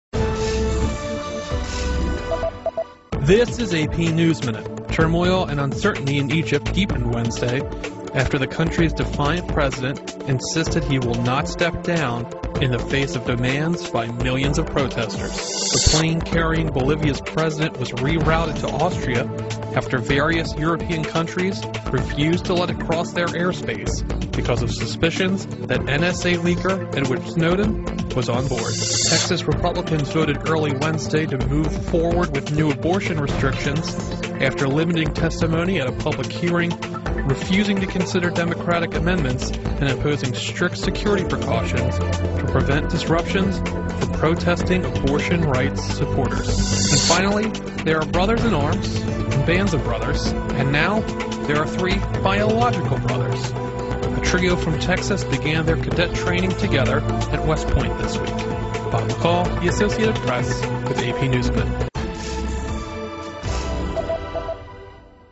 在线英语听力室美联社新闻一分钟 AP 2013-07-07的听力文件下载,美联社新闻一分钟2013,英语听力,英语新闻,英语MP3 由美联社编辑的一分钟国际电视新闻，报道每天发生的重大国际事件。电视新闻片长一分钟，一般包括五个小段，简明扼要，语言规范，便于大家快速了解世界大事。